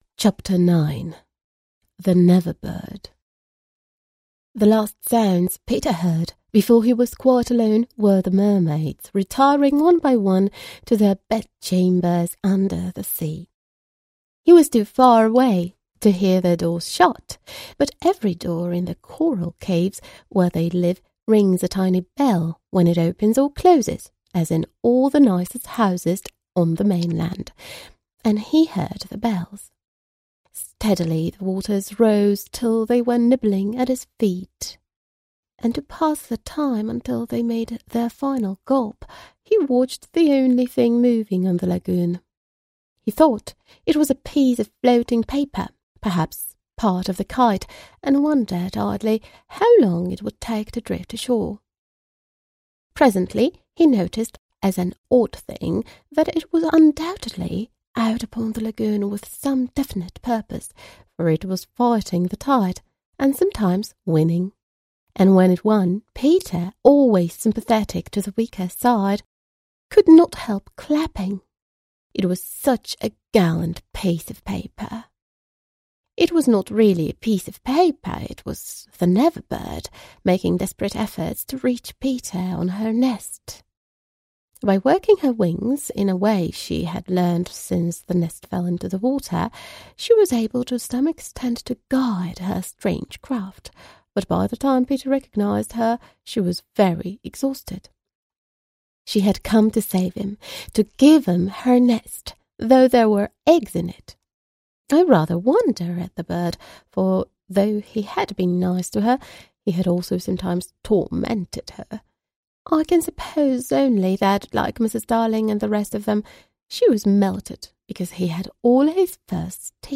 Audiobooki w języku angielskim to doskonała pomoc w ćwiczeniu wymowy oraz oswajaniu się z językiem.